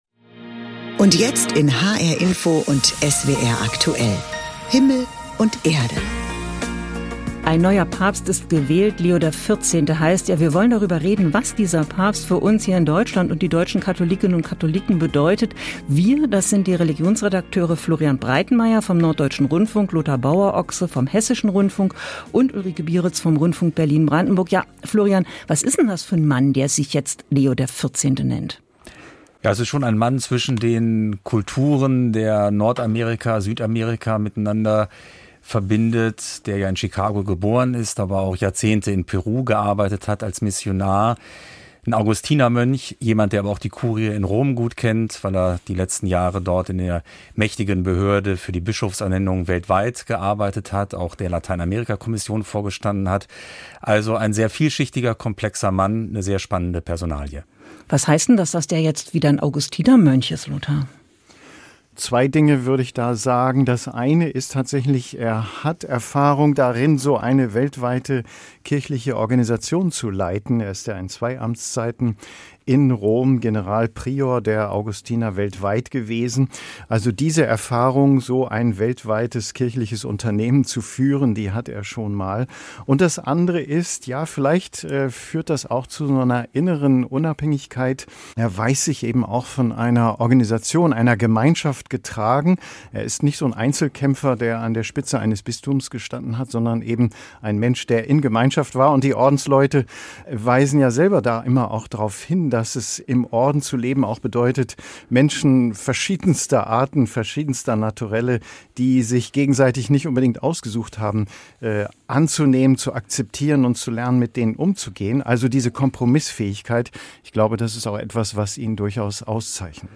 Inwiefern ist er ein Kandidat der Mitte und was bedeutet das für die anstehenden Reformen? Darüber diskutieren die ARD-Religionsredakteure